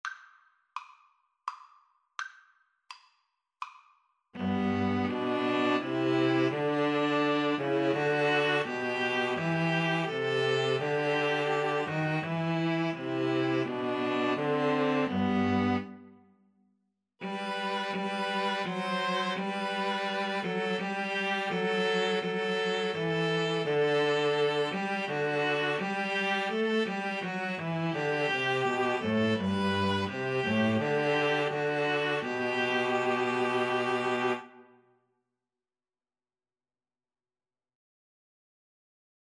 G major (Sounding Pitch) (View more G major Music for String trio )
3/4 (View more 3/4 Music)
String trio  (View more Easy String trio Music)